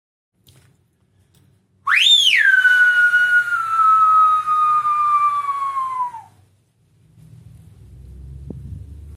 Encara que totes les persones de l’entorn, sabran a qui s’està cridant, únicament la persona al·ludida respondrà a aquesta crida, amb l’emissió de la veu “fuiooooo!